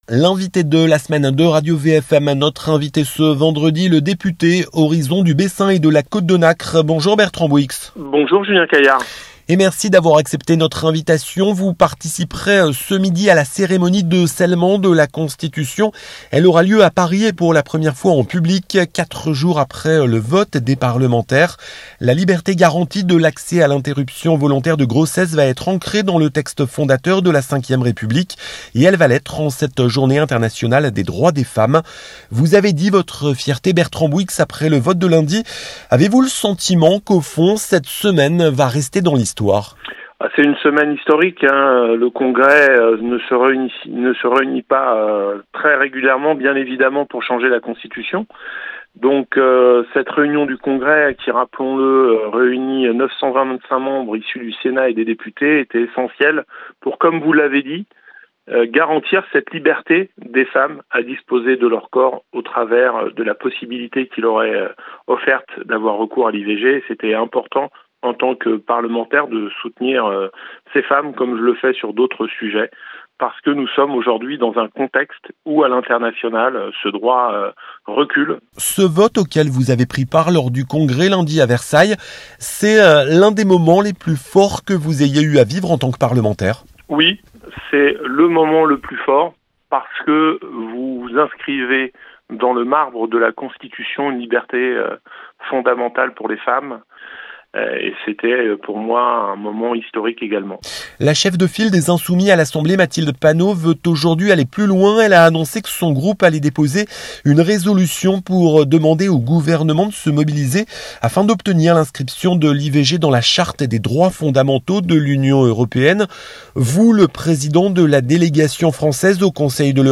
Bertrand Bouyx Bertrand Bouyx , le député Horizons du Bessin et de la Côte de Nacre est l'invité de la rédaction de Radio VFM ce vendredi 8 mars 2024, à 8 h 20 et 12 h 20 !